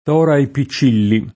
t0ra e ppi©©&lli] (Camp.)